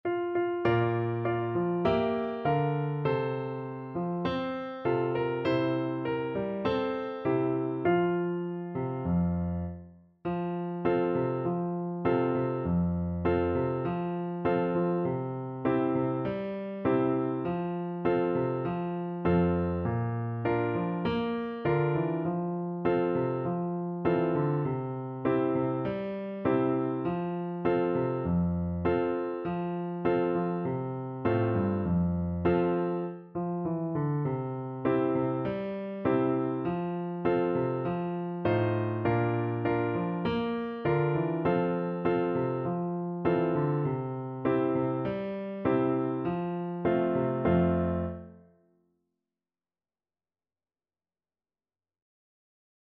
4/4 (View more 4/4 Music)
Moderato